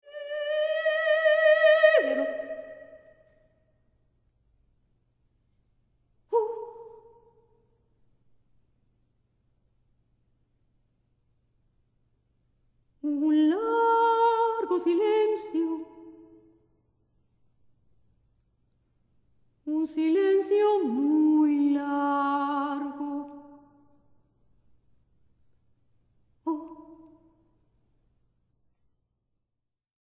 Música de Cámara